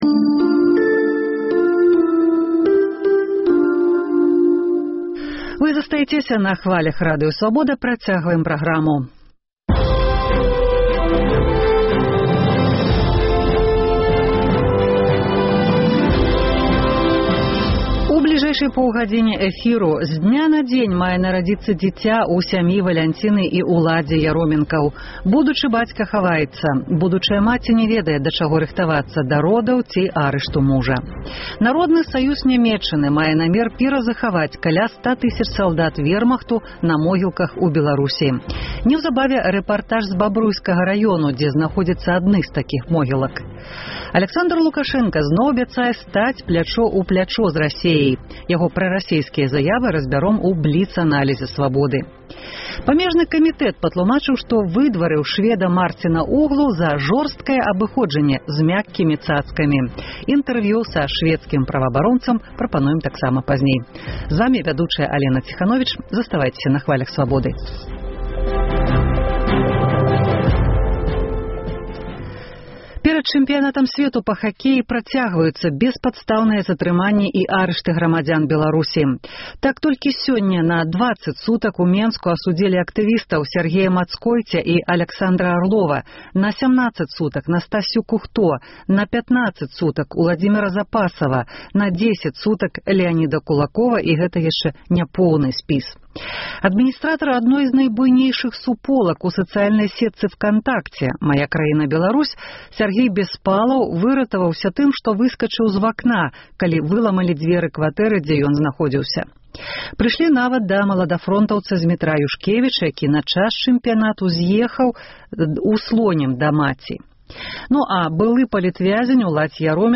Рэпартаж зь вёскі Шчаткава пад Бабруйскам.
Апытаньне ў Гомелі.